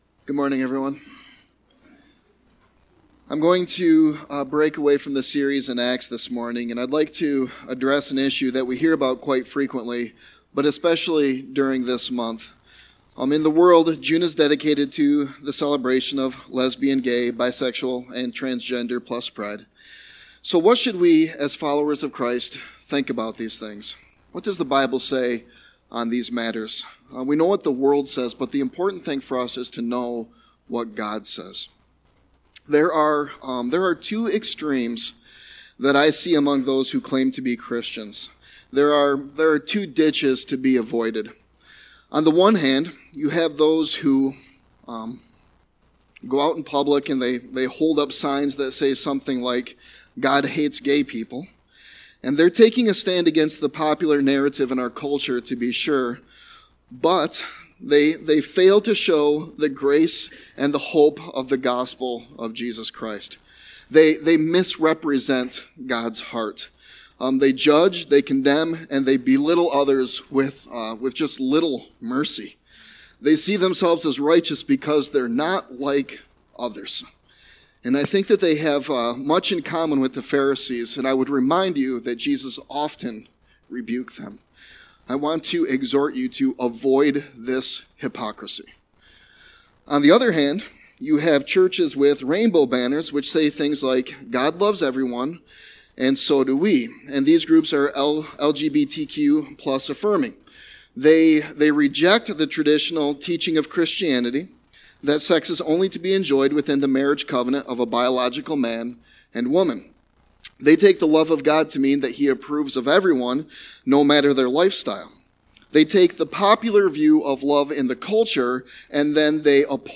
2024 Sermon Text: Various Texts Teacher